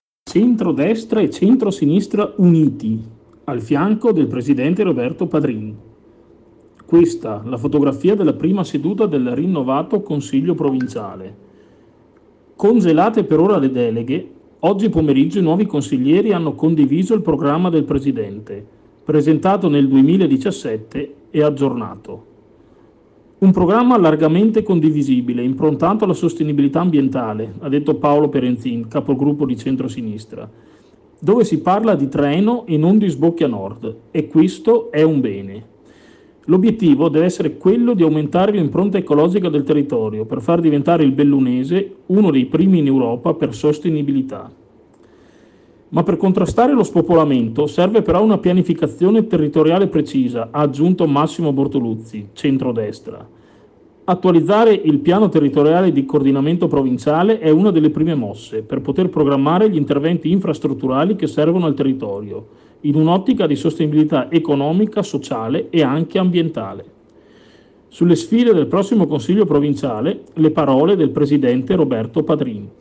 DAL CONSIGLIO PROVINCIALE SERVIZI E COMMENTI